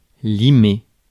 Ääntäminen
IPA : /paʊnd/ US : IPA : [paʊnd]